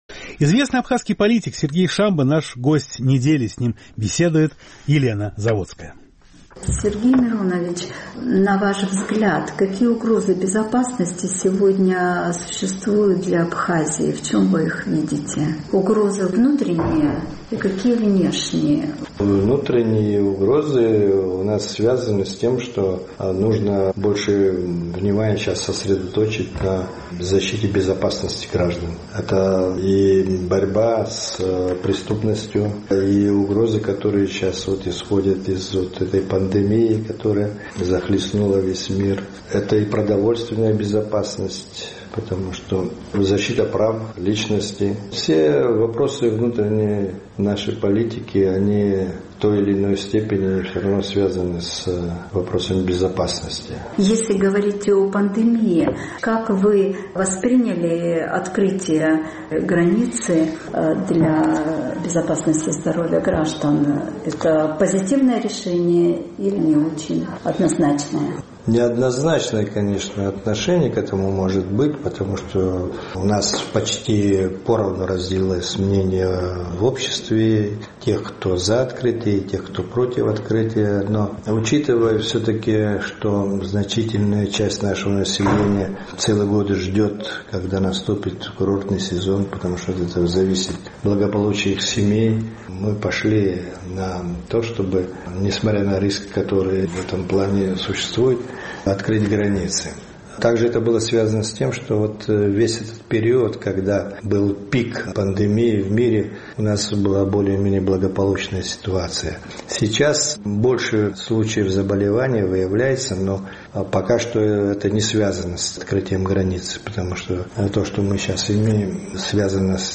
Гость недели – Сергей Шамба